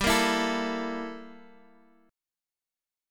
Gm6add9 Chord
Listen to Gm6add9 strummed